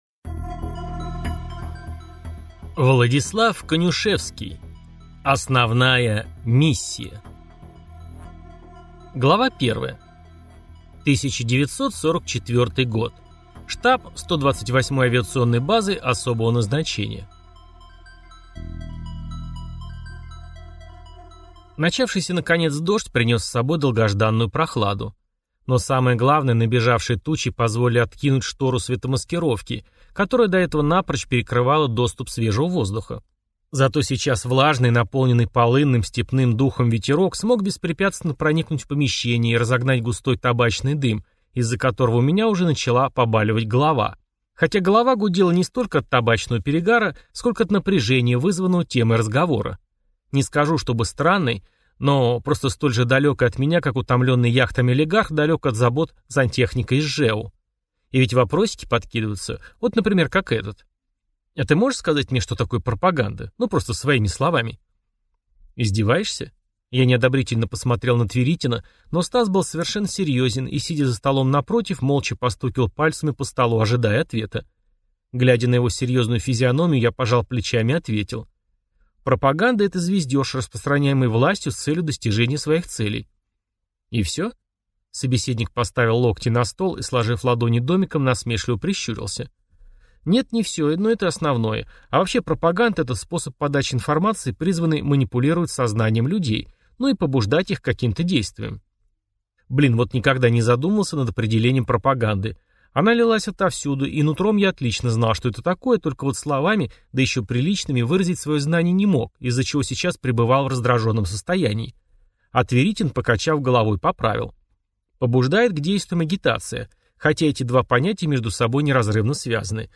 Аудиокнига Попытка возврата. Основная миссия | Библиотека аудиокниг